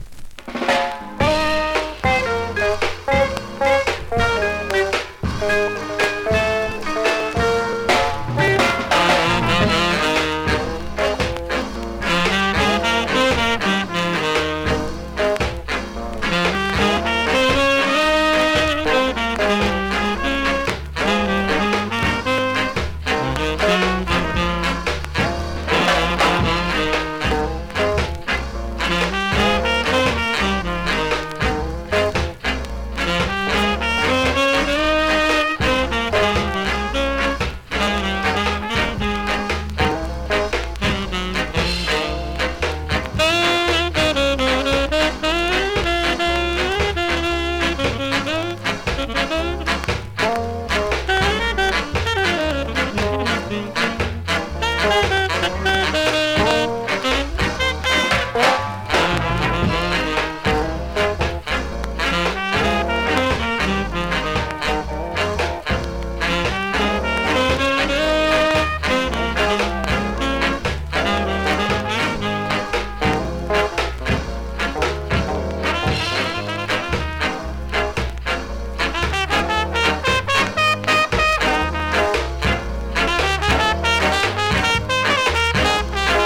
フチに少しHEAT DAMAGE、少し針がブレます)   コメントレアSKA!!YELLLOW VINYL!!
スリキズ、ノイズそこそこあります。